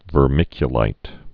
(vər-mĭkyə-līt)